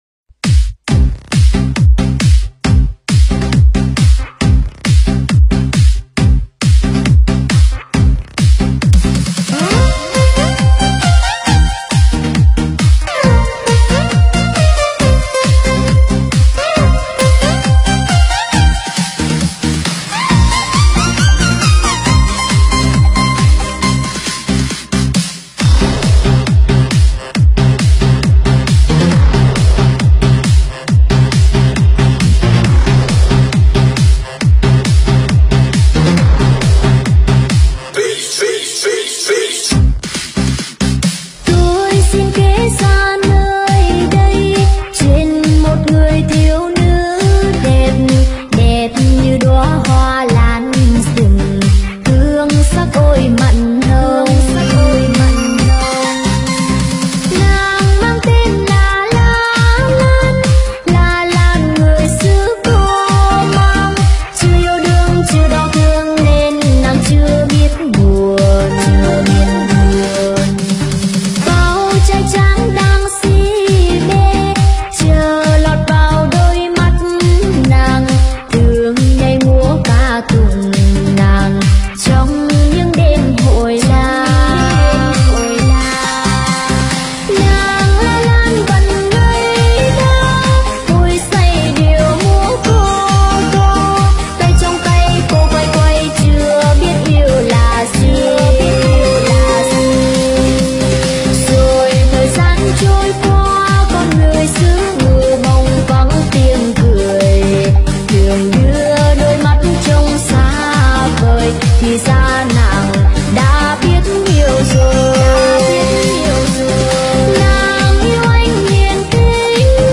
Nhạc Việt Remix